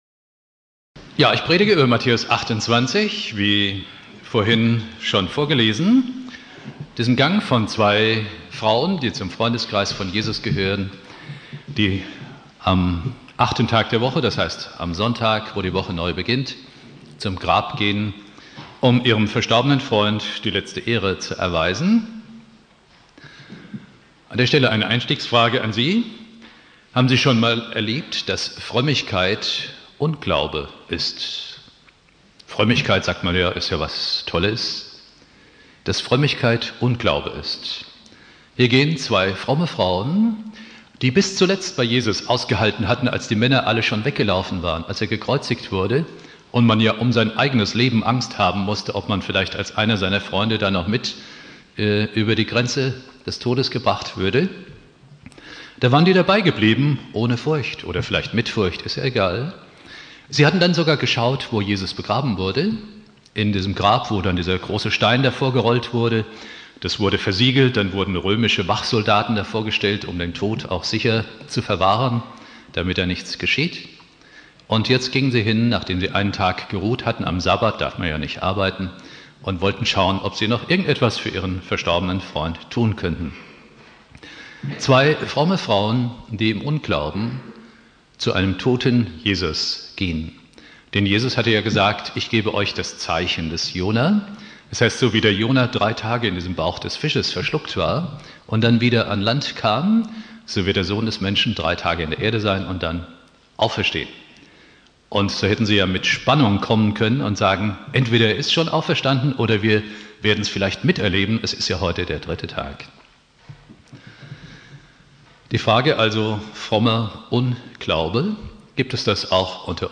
Predigt
Ostersonntag